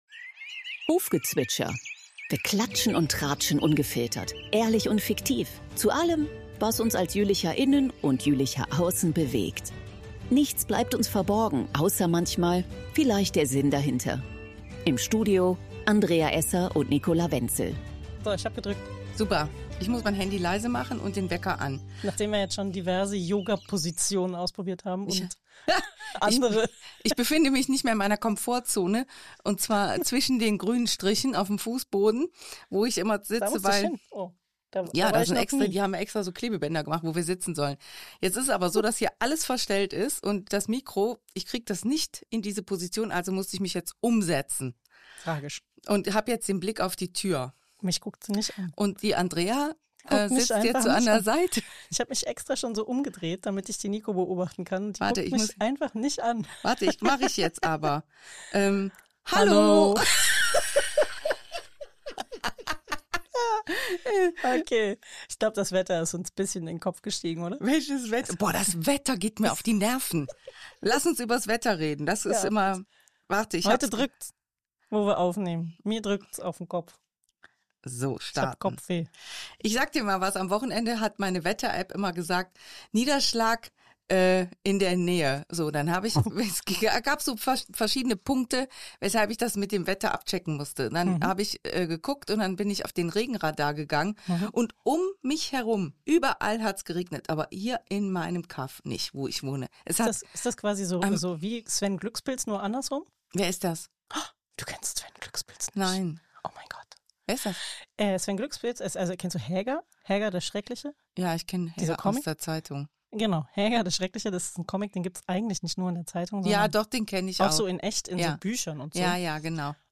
Was alles passiert ist und noch passieren soll hat sich das Duo ausgetauscht. Aktuell zum Tag geht es auch um Schatten, den man beim tropischen Wetter suchen sollte und warum Freitag, der 13., gar nicht so unglücklich ist.